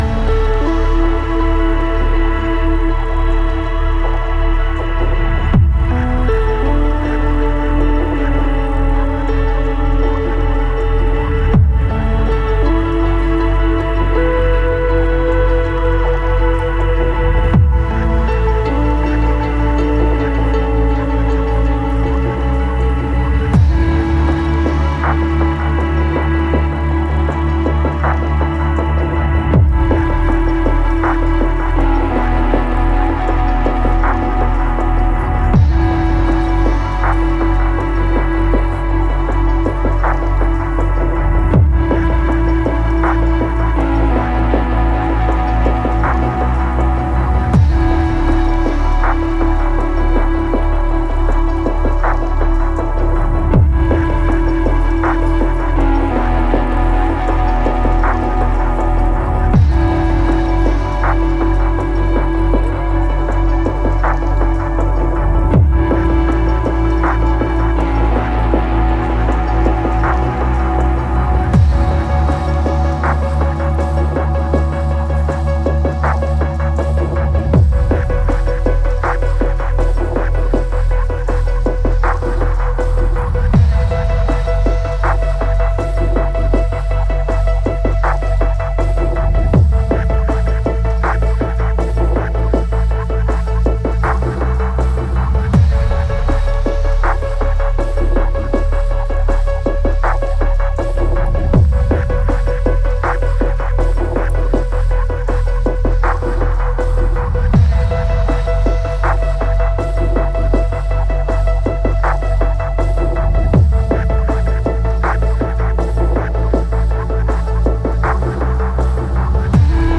rich, synth-soaked near-ambient mix
Ambient